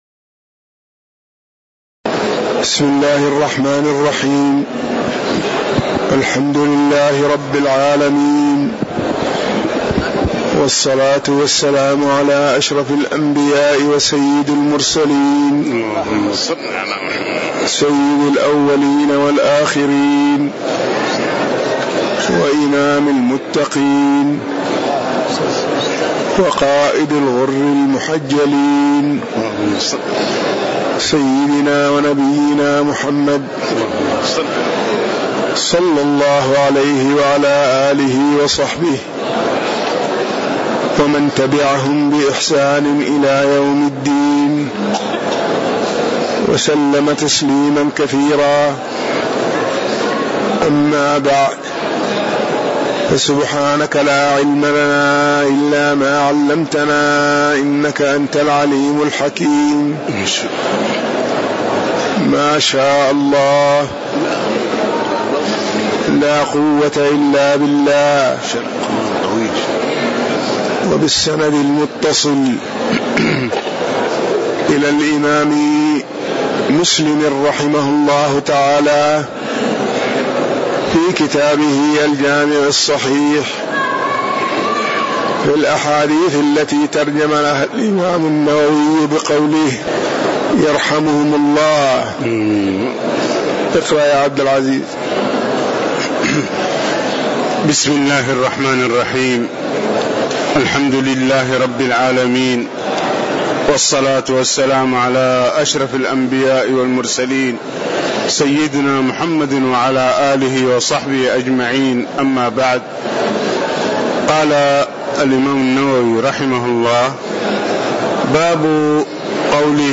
تاريخ النشر ١ جمادى الأولى ١٤٣٨ هـ المكان: المسجد النبوي الشيخ